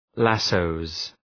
Shkrimi fonetik {‘læsəʋz}